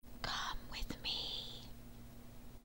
描述：女声说"get funkyquot。
标签： 136 bpm Fusion Loops Vocal Loops 608.32 KB wav Key : Unknown
声道立体声